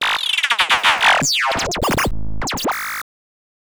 Glitch FX 18.wav